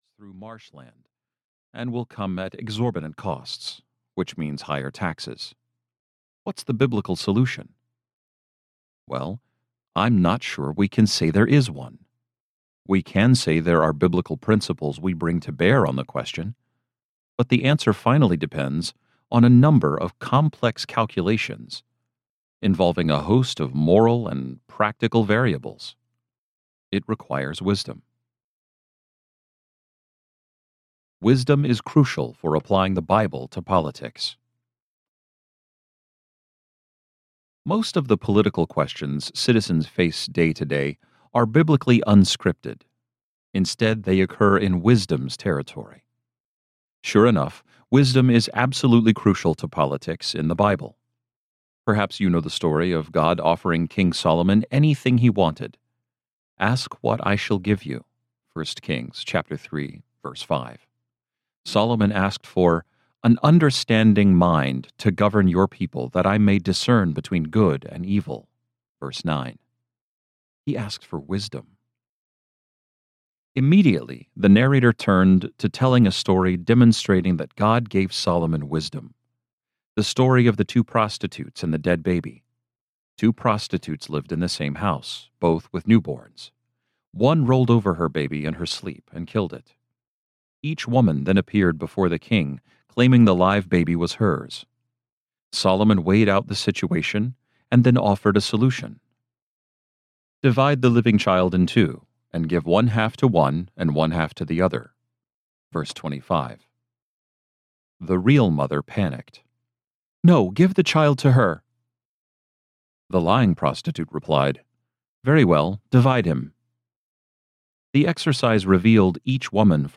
How the Nations Rage Audiobook
Narrator
8.77 Hrs. – Unabridged